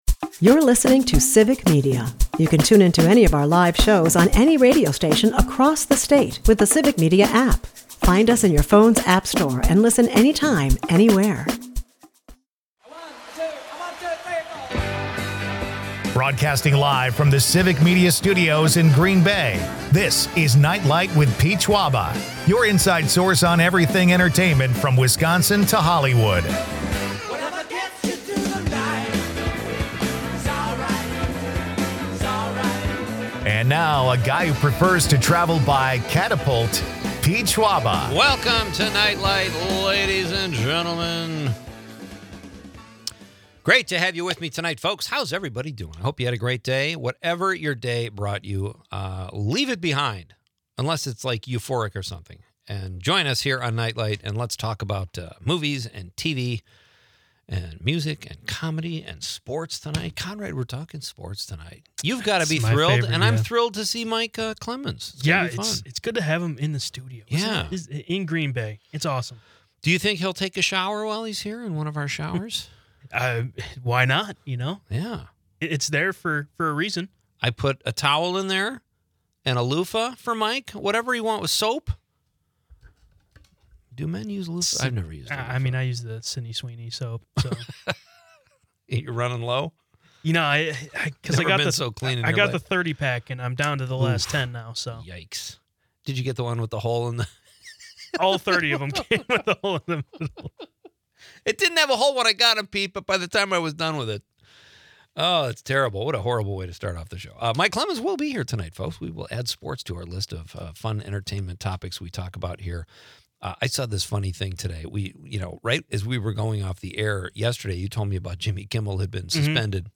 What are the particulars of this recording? The episode wraps with audience participation, asking for favorite musical movie moments—an eclectic mix of entertainment and nostalgia.